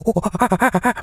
monkey_chatter_18.wav